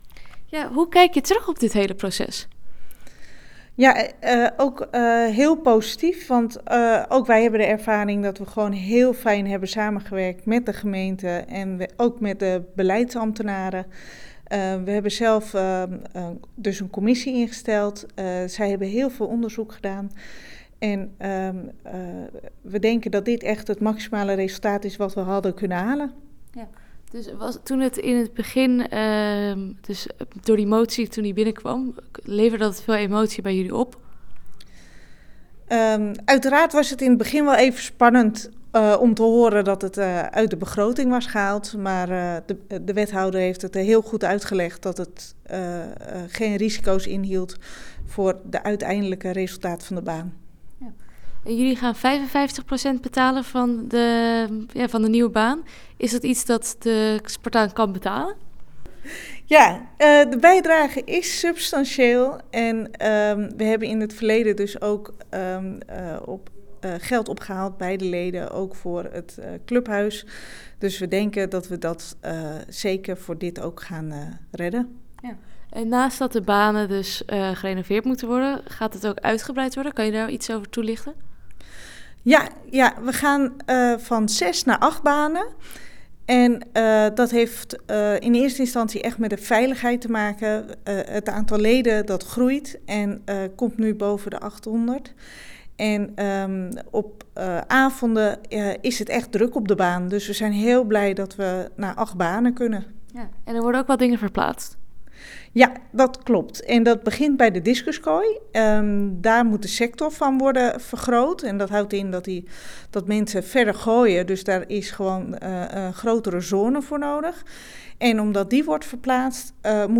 Audioreportage